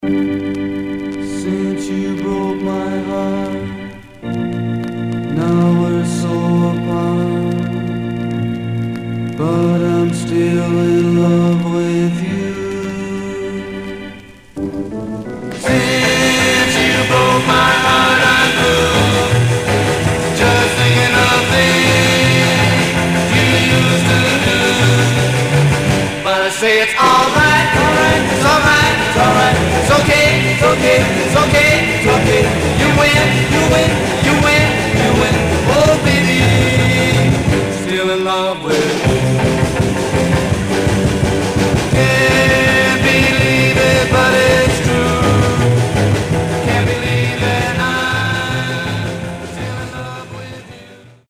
Some surface noise/wear
Mono
Garage, 60's Punk